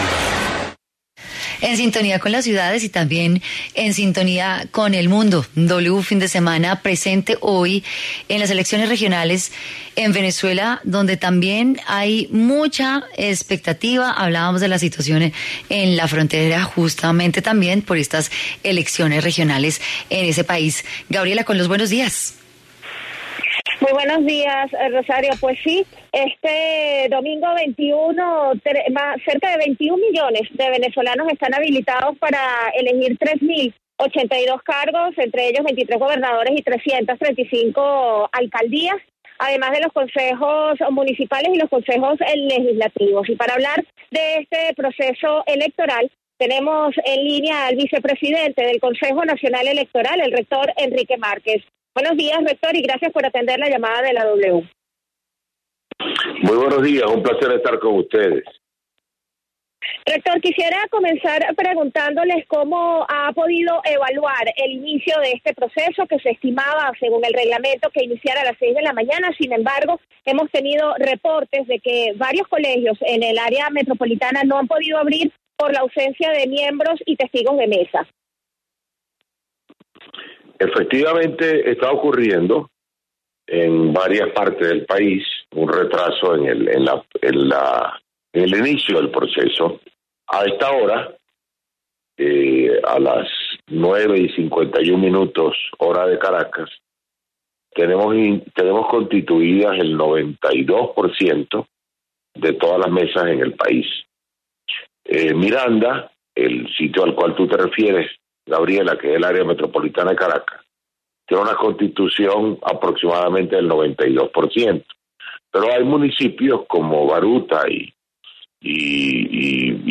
W Fin Semana conversó con, Enrique Márquez, vicepresidente del CNE, sobre los estos comicios electorales que han sido tildados por la opinión de “fraudulentos”.